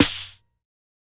SNARE_WEBACK.wav